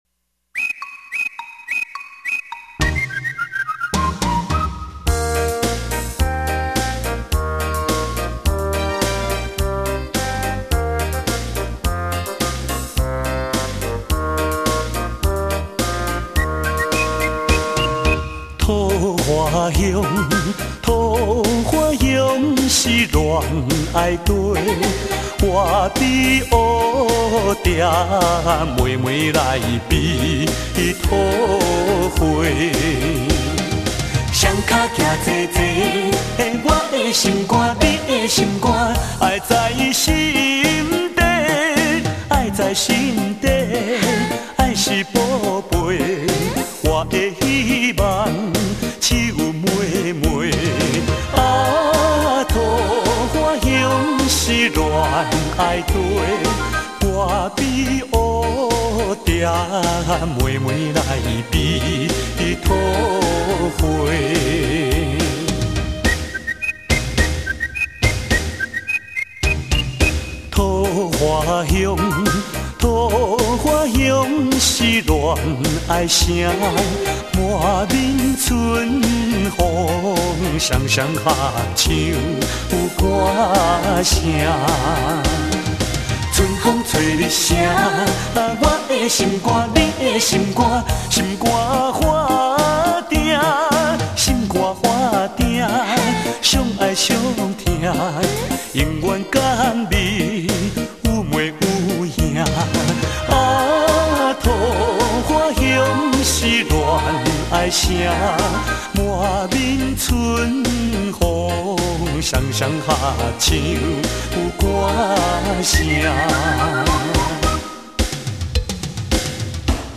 金曲歌王+金牌老歌。